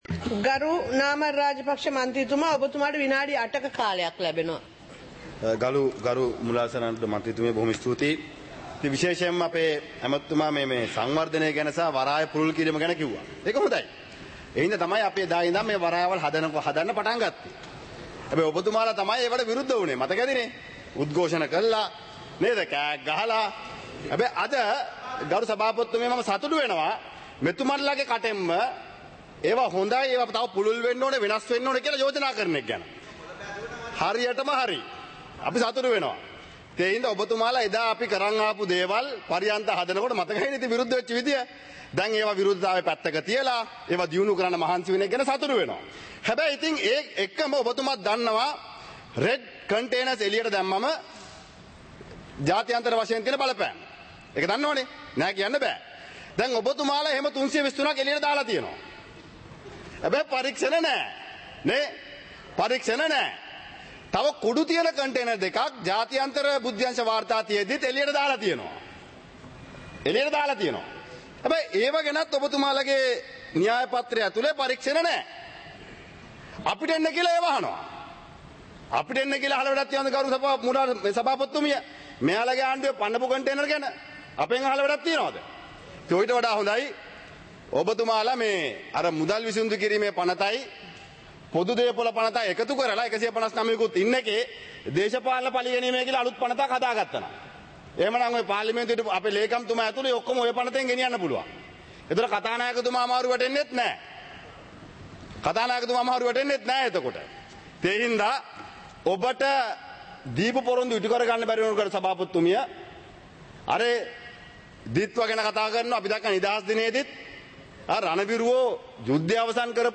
Parliament Live - Recorded